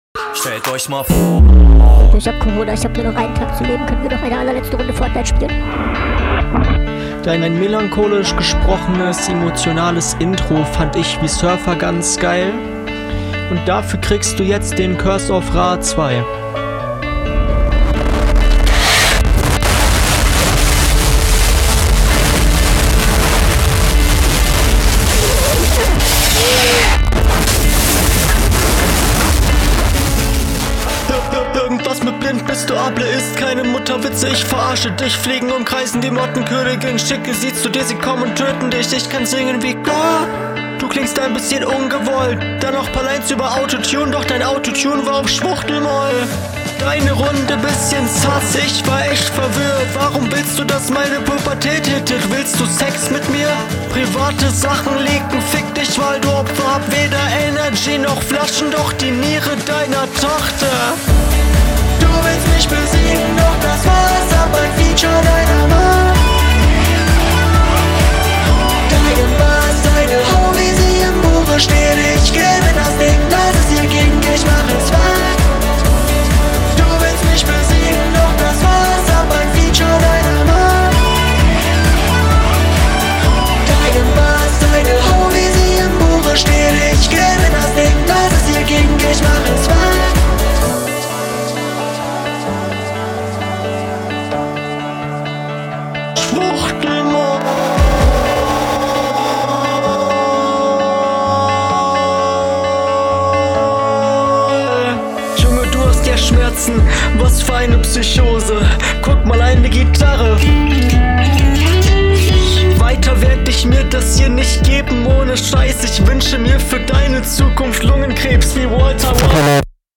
was soll dieser übersteuerte Teil, mal im ernst? damit Fickst du einfach nur jeden der …